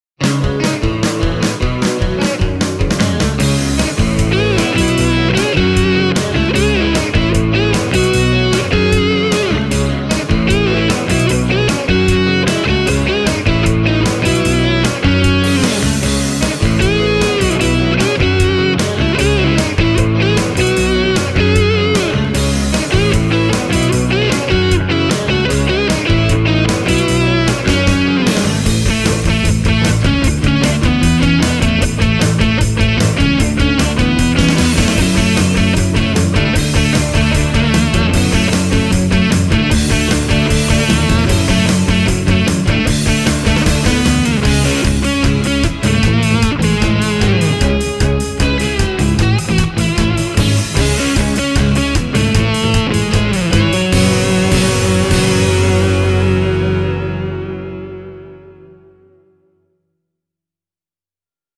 And here’s the demo song – with and without the other instruments: